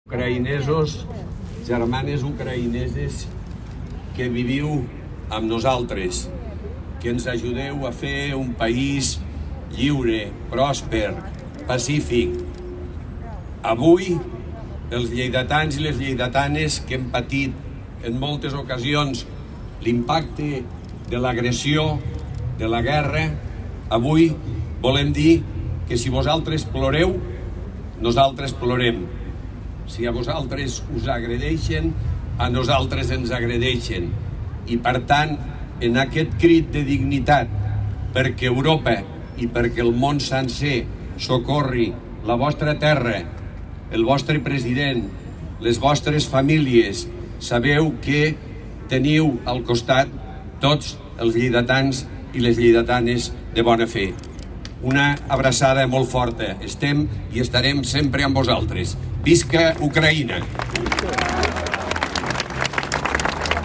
L’alcalde Miquel Pueyo participa en la concentració de la plaça Ricard Viñes, un acte que defineix com un “crit de dignitat”, perquè Europa i el món sencer socorri aquesta terra i les seves famílies
Tall de veu M. Pueyo
tall-de-veu-miquel-pueyo